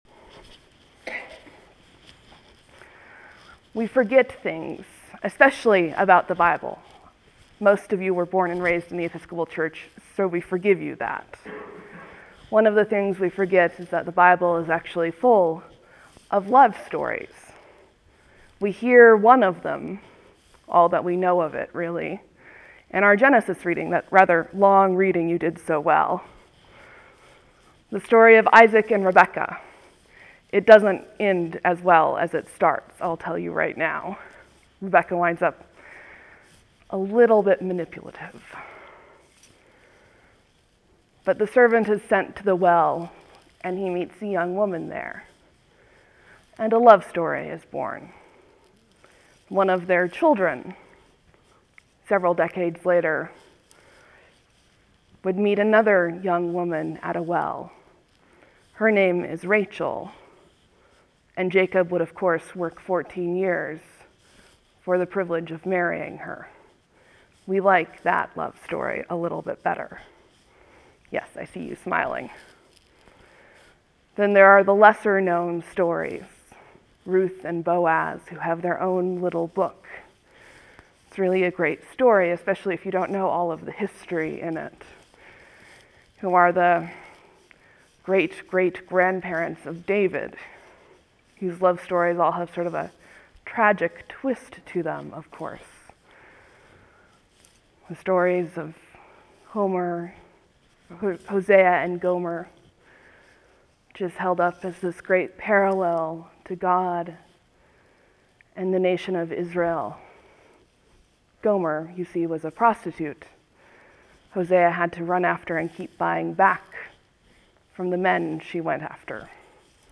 (There will be a few seconds of silence before the sermon starts. Thank you for your patience.)